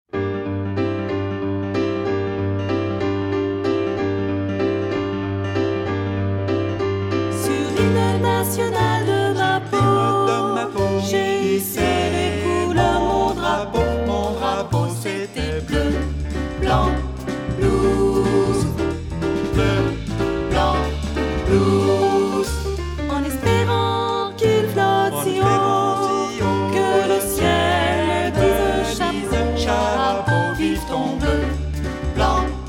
15 tracks arranged for mixed choir and jazz trio